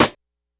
snr_16.wav